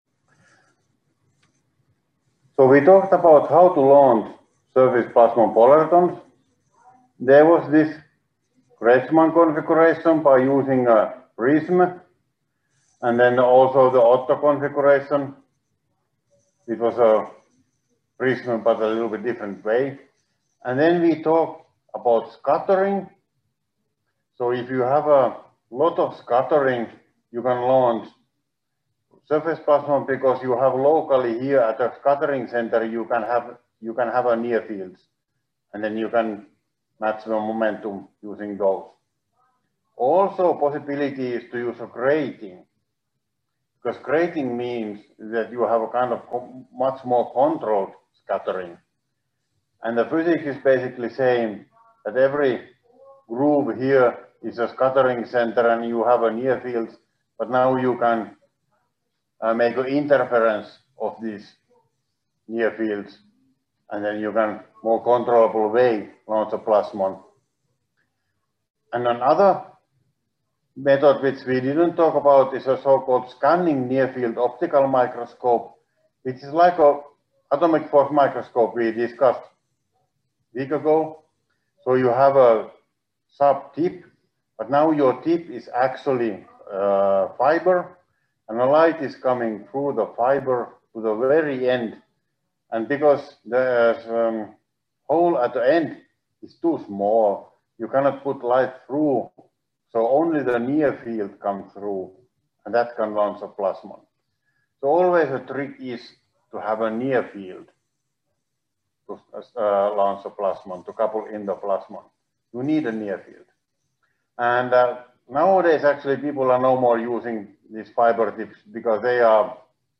Lecture 3, part 3 — Moniviestin